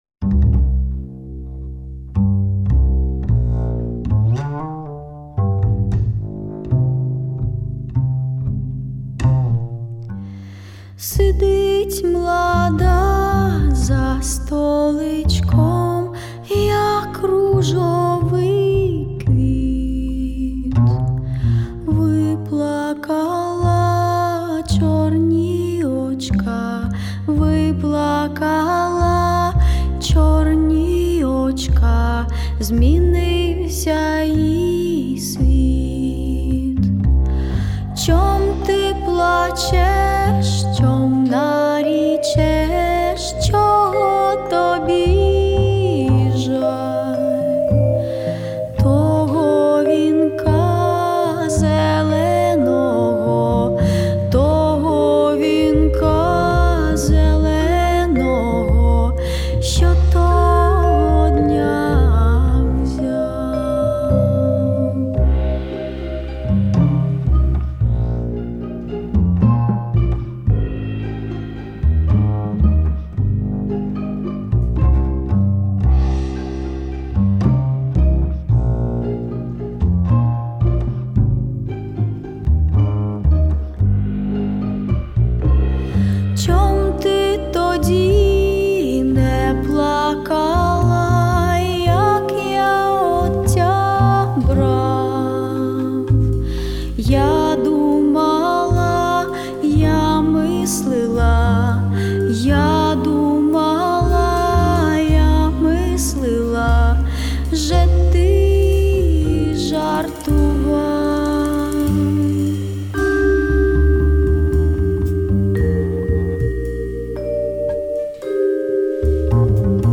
джаз, Етно, поп, співачка